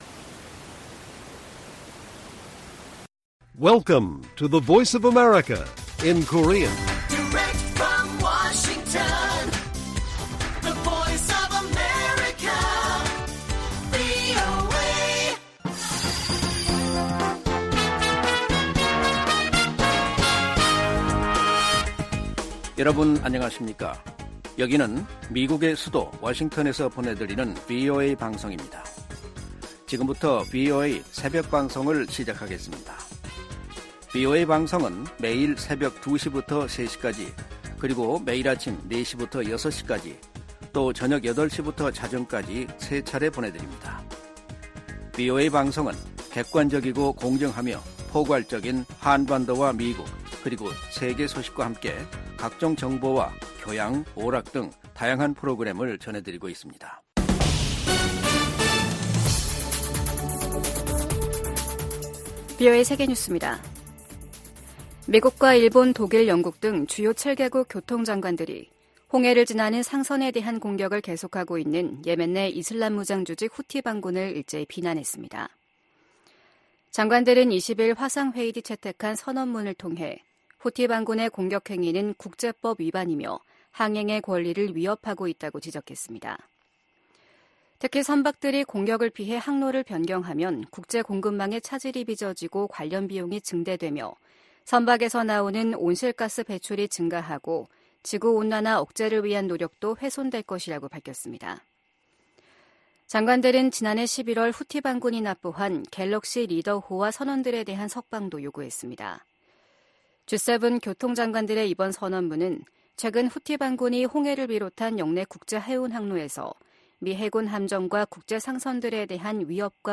VOA 한국어 '출발 뉴스 쇼', 2024년 2월 22일 방송입니다. 러시아가 우크라이나 공격에 추가로 북한 미사일을 사용할 것으로 예상한다고 백악관이 밝혔습니다. 미국 정부는 북일 정상회담 가능성에 역내 안정에 기여한다면 환영할 일이라고 밝혔습니다.